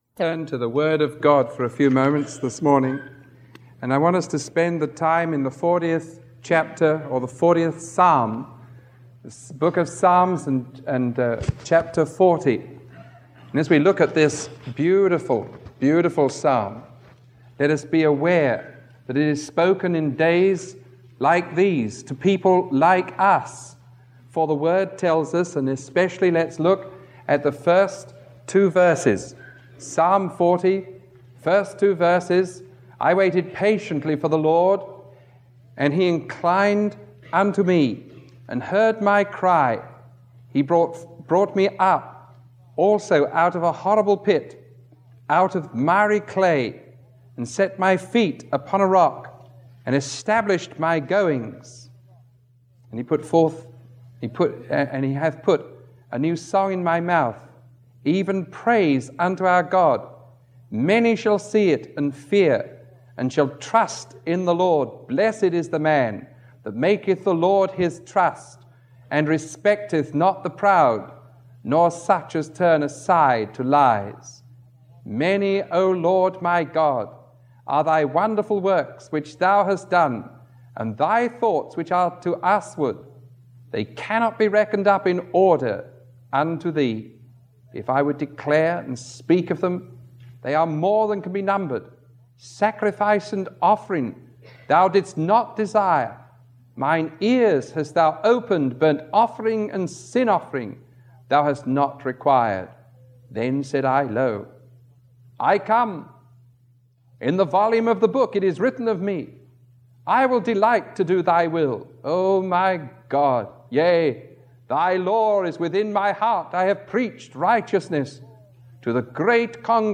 Sermon 0175A recorded on May 25